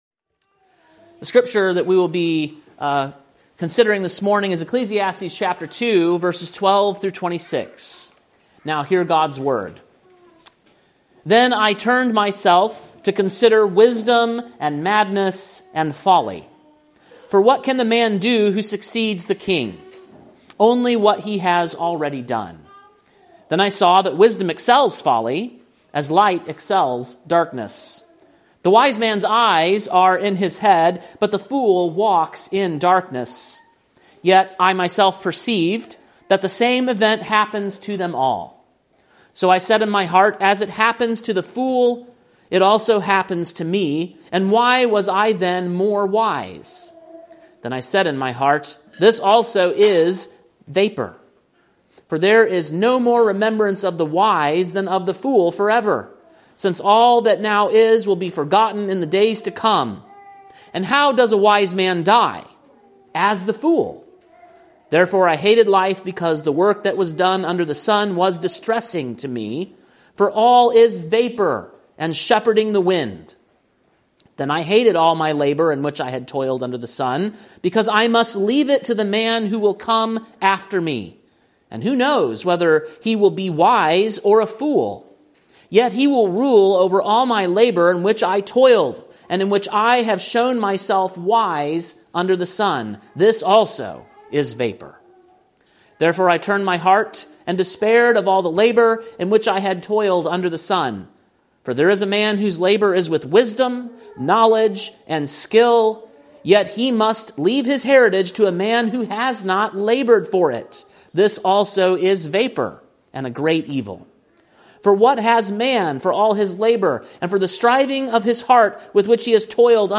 Sermon preached on November 30, 2025, at King’s Cross Reformed, Columbia, TN.